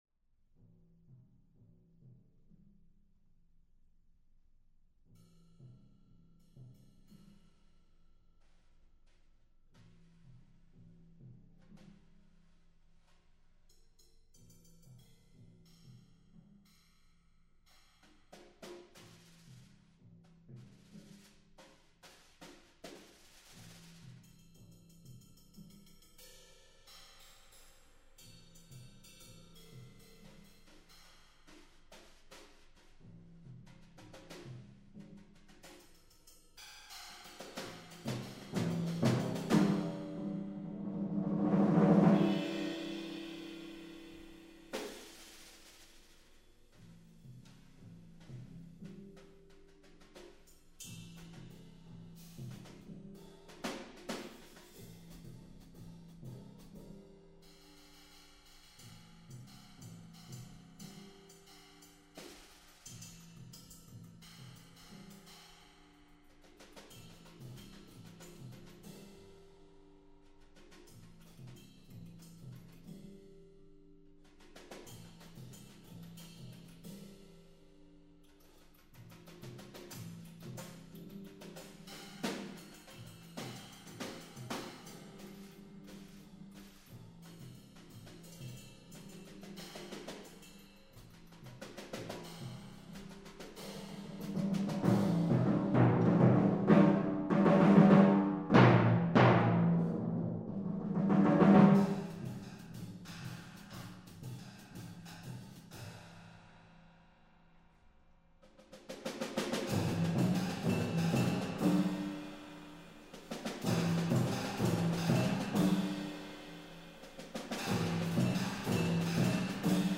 first three minutes (starts very quiet)